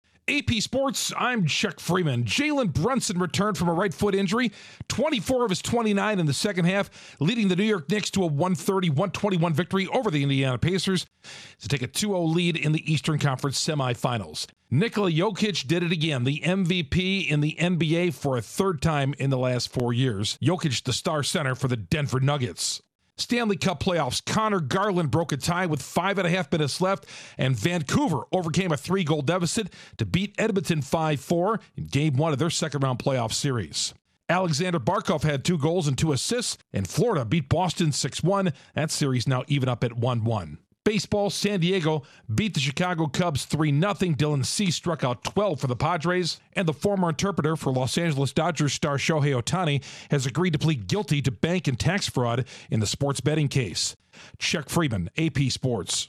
Sports News